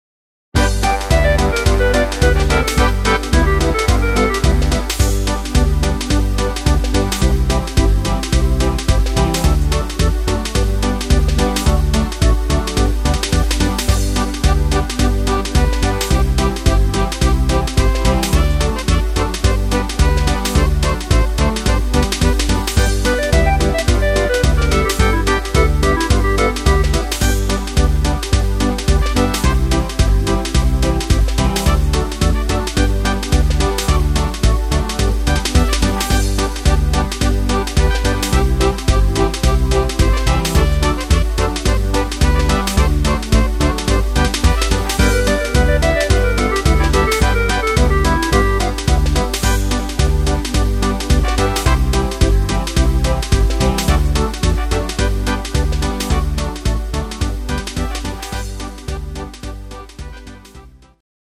Im Stil von  Traditionell
Rhythmus  Marsch Dixie
Art  Traditionell, Deutsch